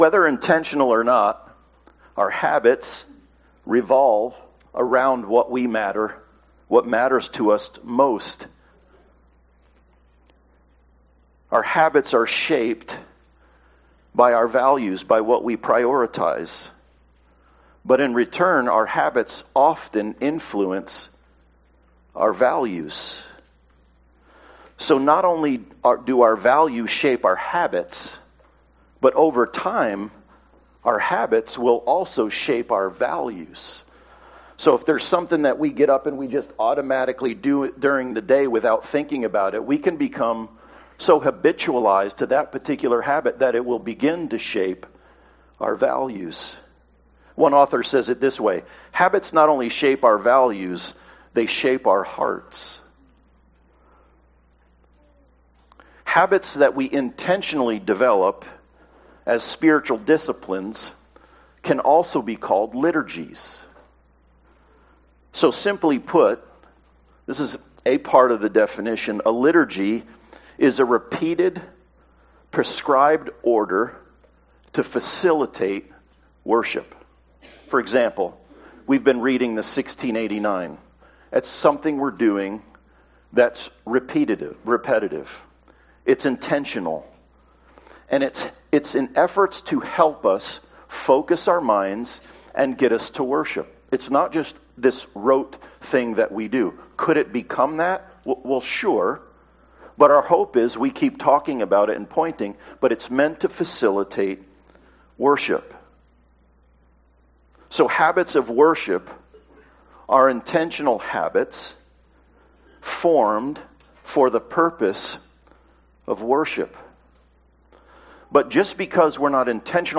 Habits shape our values and hearts. Intentional habits (liturgies) are acts of worship that align us with God's Truth. The goal of this sermon is to encourage intentional habits - or liturgies - that help us know and live God's truth.